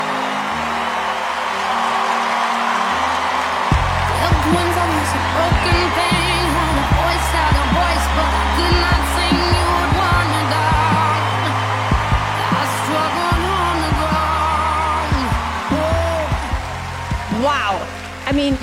10th Jul 2023 10:58 pm GMT+0300 permalink Iš talentų šou.